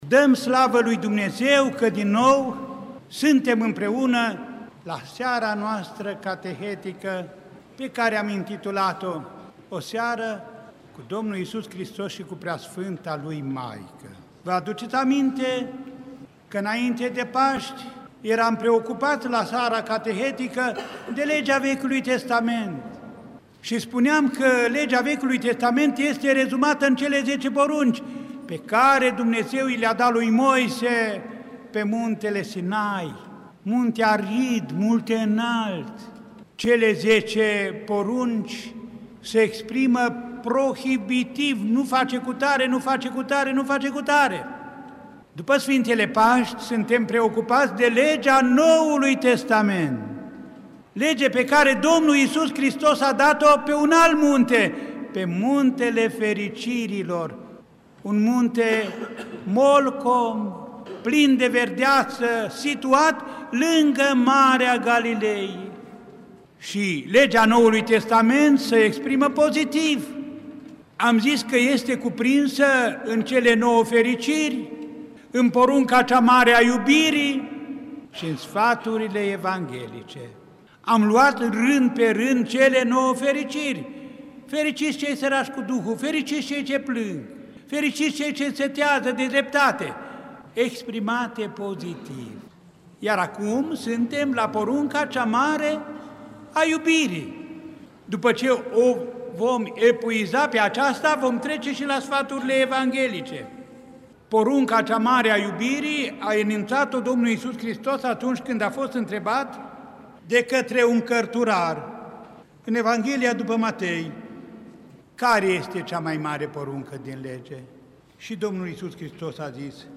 Cuvinte de învățătură ale Înaltpreasfințitului Părinte Arhiepiscop și Mitropolit Andrei, rostite în Catedrala Mitropolitană din Cluj-Napoca, în fiecare duminică seara, în cadrul programului catehetic